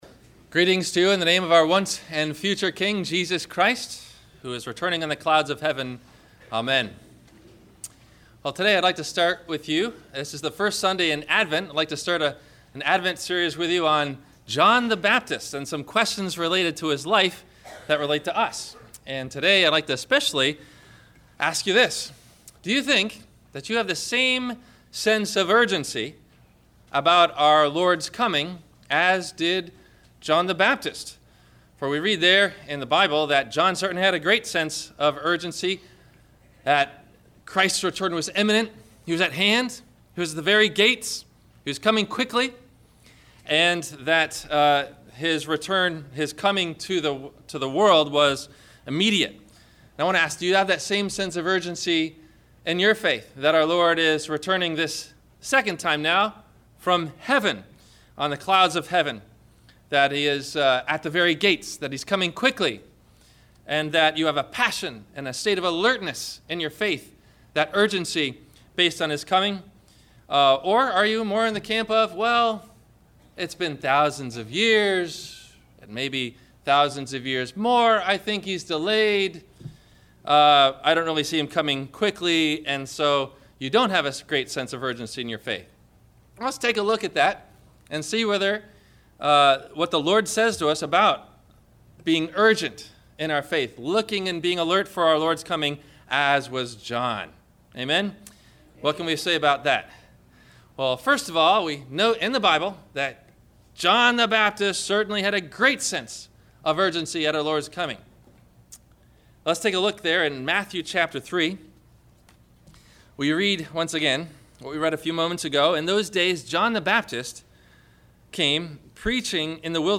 Do You Have A Sense of Urgency About Jesus Coming? – Sermon – November 30 2014